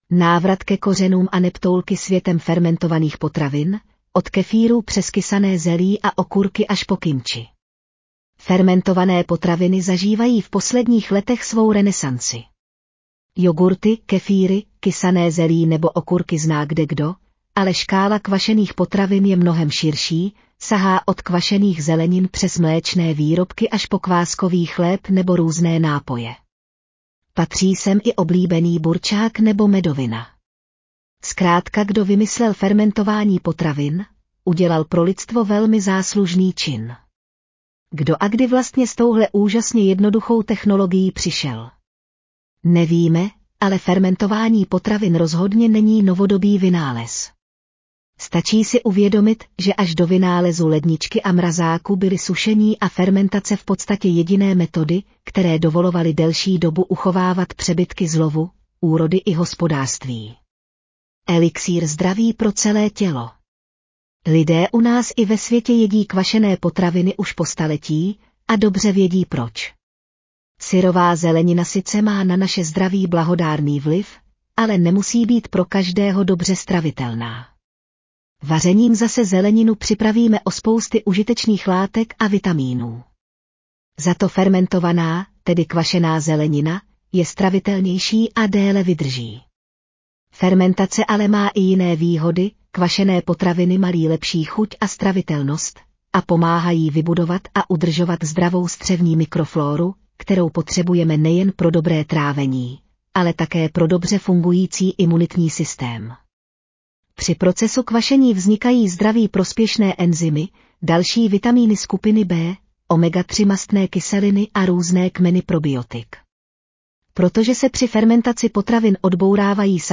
Audio verze článku Návrat ke kořenům aneb toulky světem fermentovaných potravin: od kefírů přes kysané zelí a okurky až